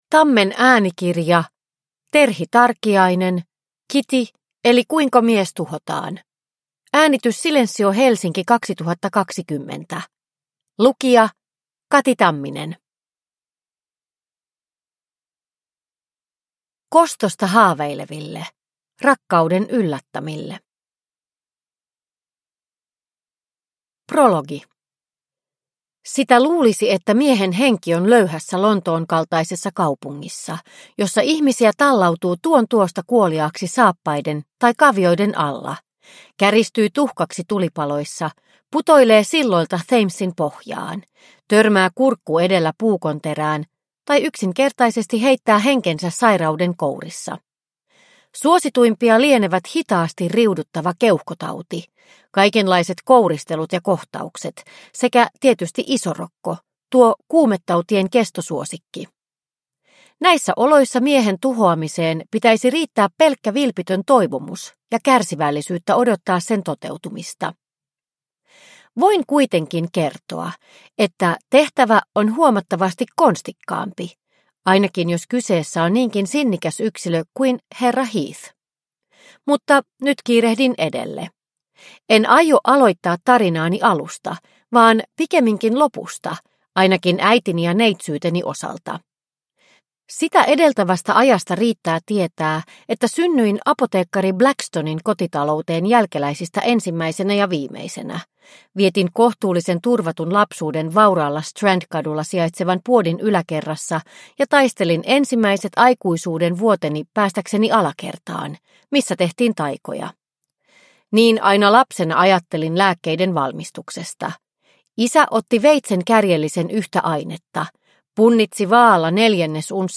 Kitty – Ljudbok – Laddas ner